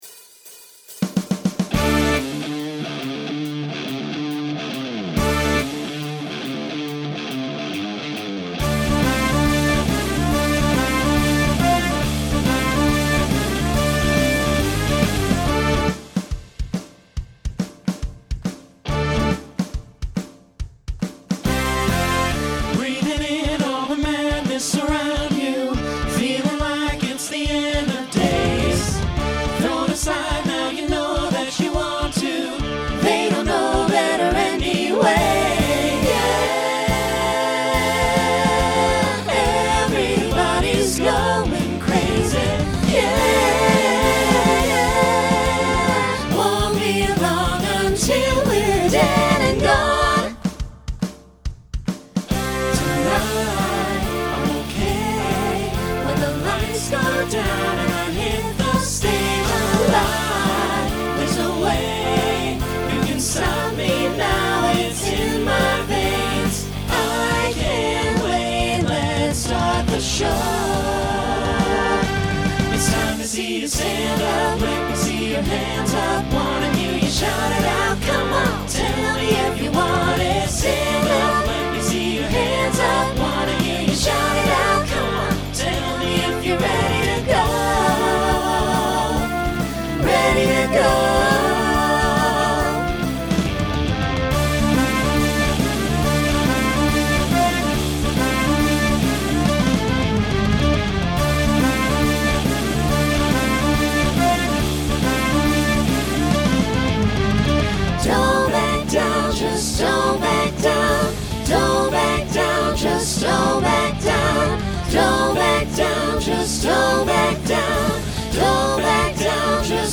Genre Rock
Opener Voicing SATB